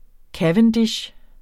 Udtale [ ˈkavəndiɕ ]